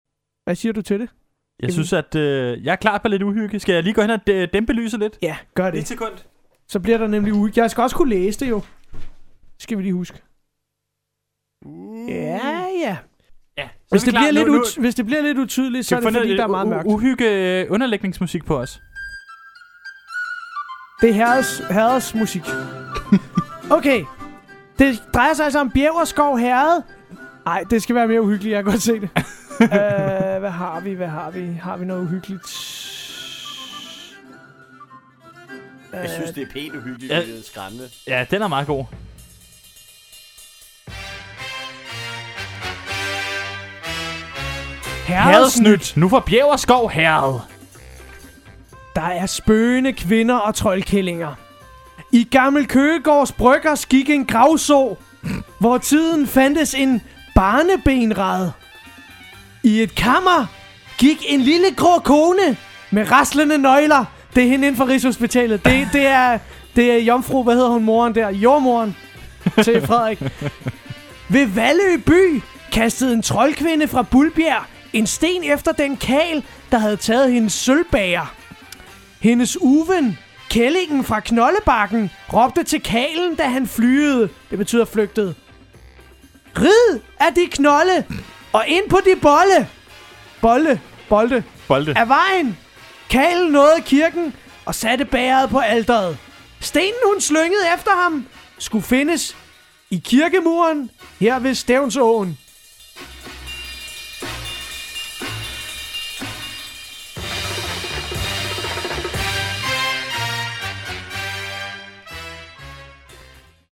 Radioti 92,9 MHz og 88,9 kabel